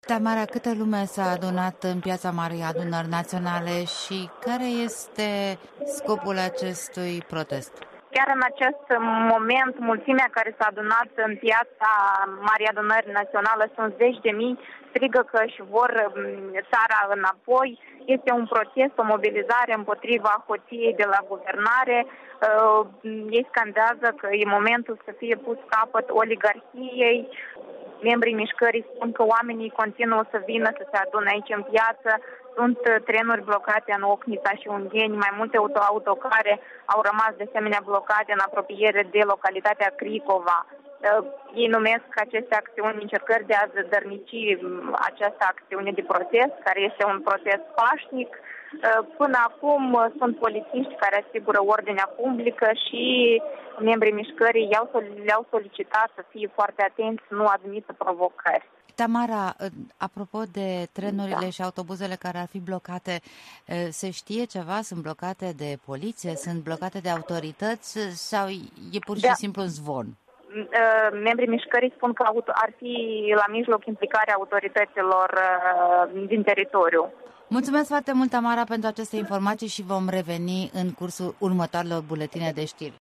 În direct de la marea demonstrație de protest „Demnitate și adevăr”